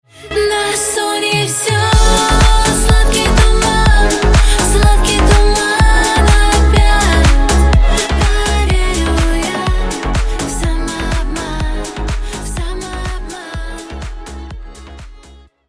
• Качество: 128, Stereo
поп
красивый женский голос
цикличные